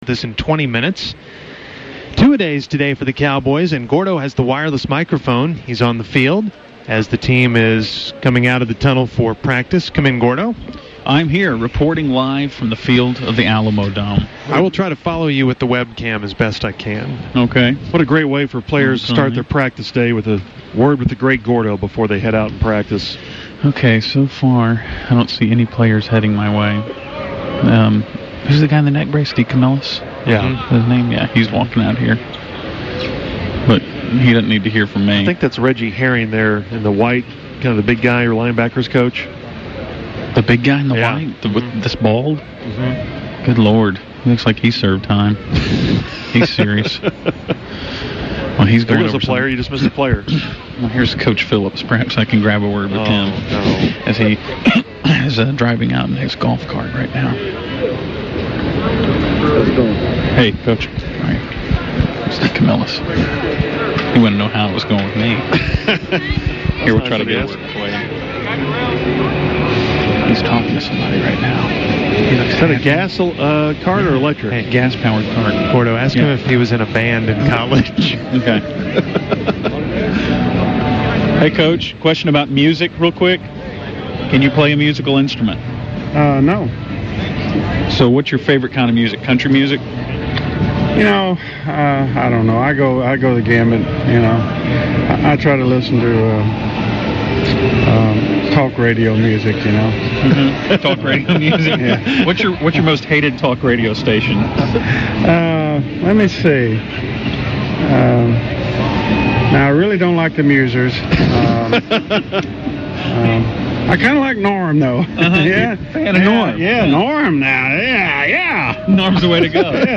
He starts out with a hard hitting interview with Wade Phillips.